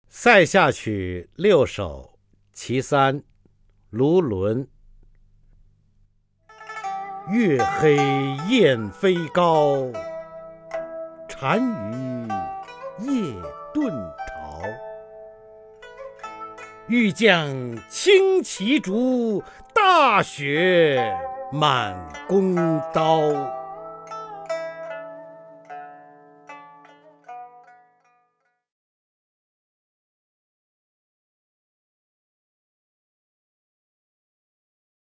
方明朗诵：《塞下曲六首·其三》(（唐）卢纶) （唐）卢纶 名家朗诵欣赏方明 语文PLUS